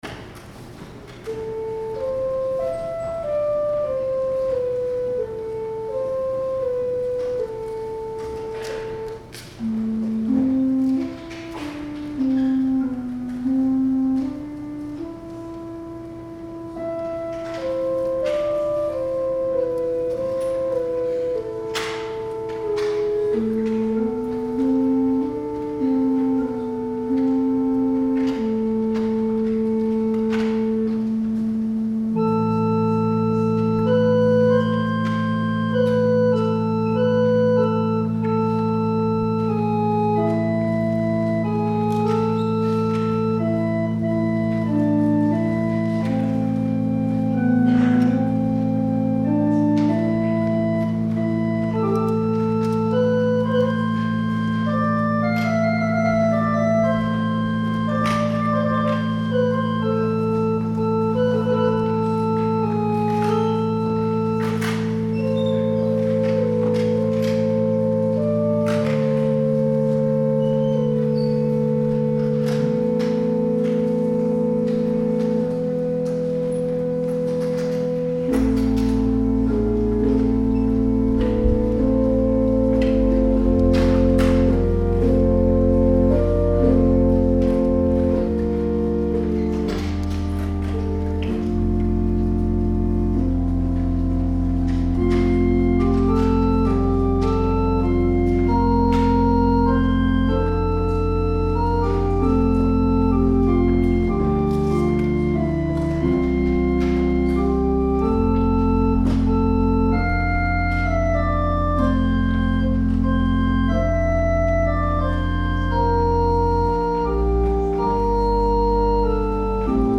Music From February 3, 2019 Sunday Service
Communion Improv
Organ Music